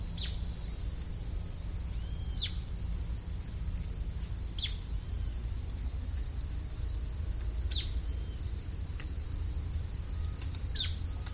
Syndactyla rufosuperciliata acrita
English Name: Buff-browed Foliage-gleaner
Detailed location: Reserva Natural Puertos
Condition: Wild
Certainty: Recorded vocal